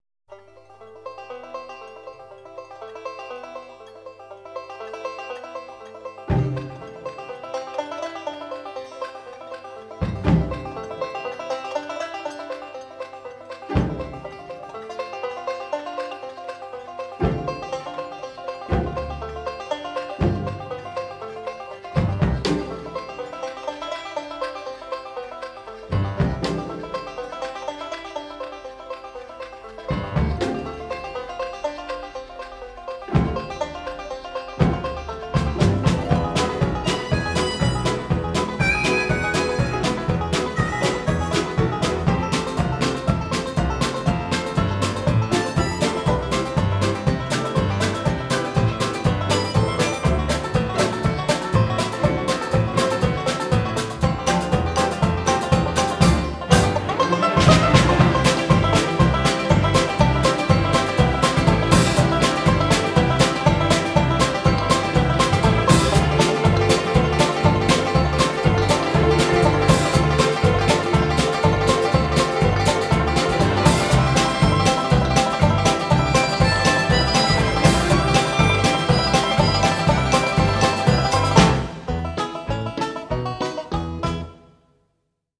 banjo
harmonica